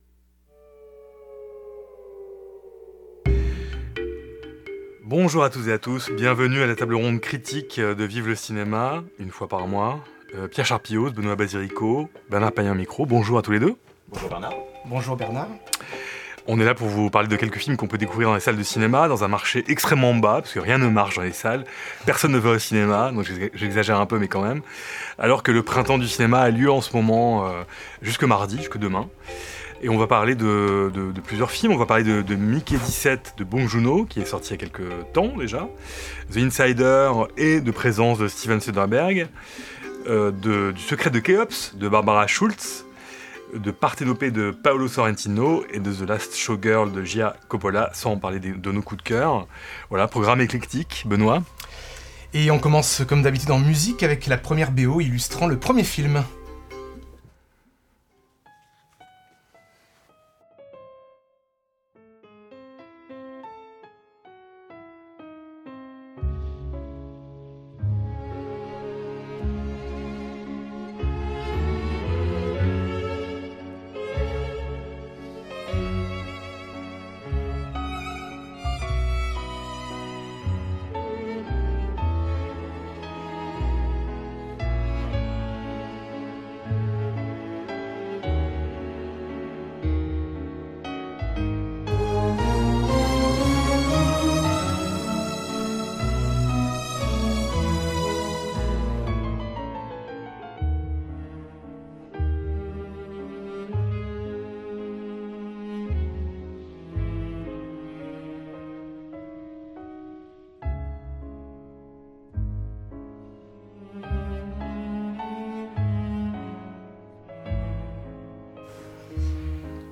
Table ronde critique